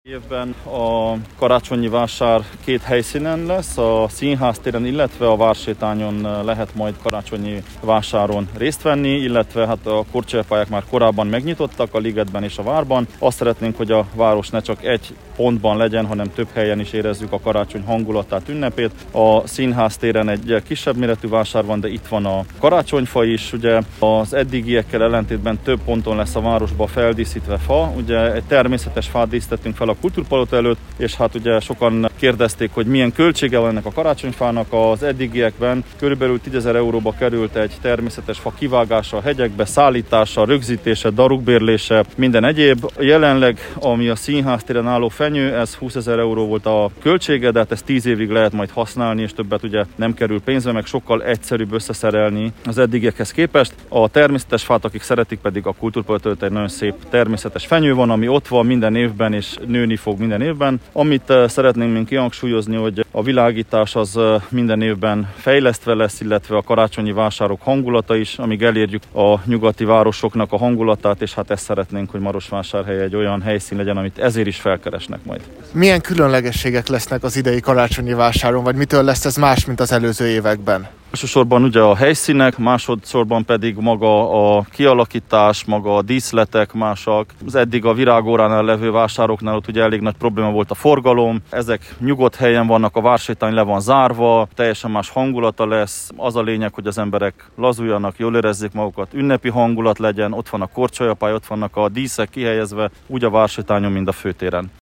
Soós Zoltánt Marosvásárhely polgármesterét kérdeztük a vásár megnyitóján.